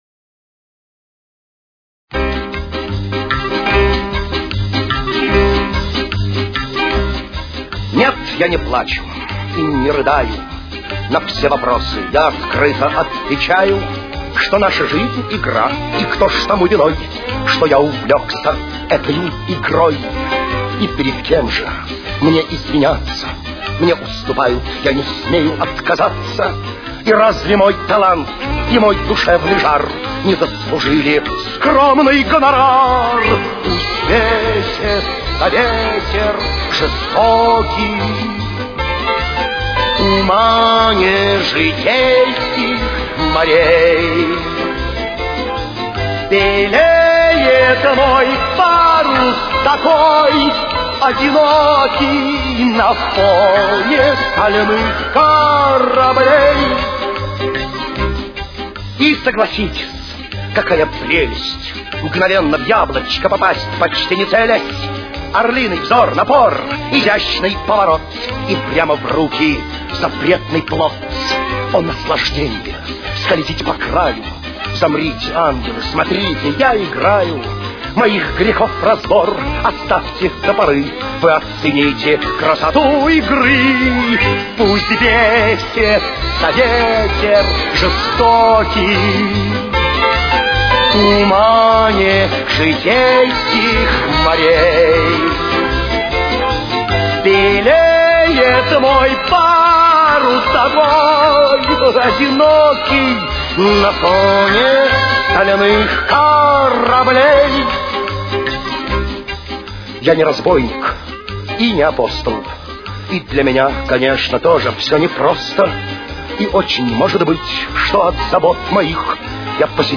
с очень низким качеством
До минор. Темп: 154.